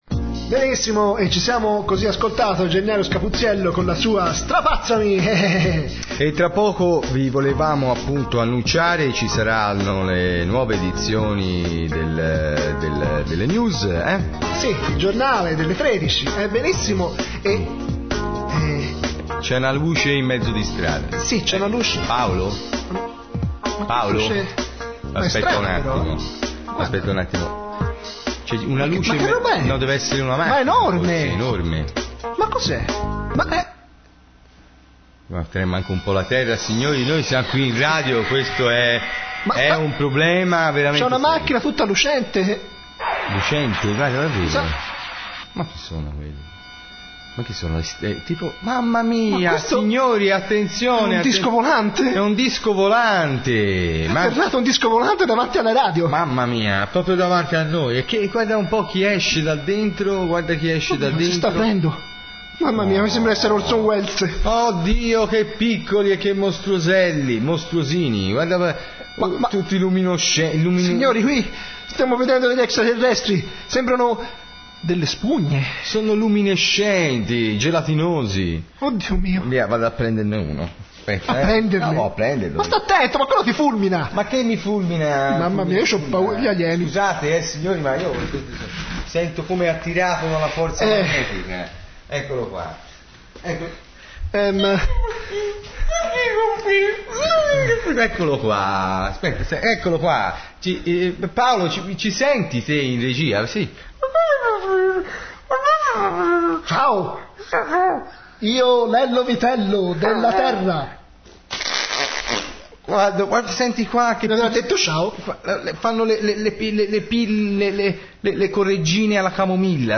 Un Radiodramma di 12 minuti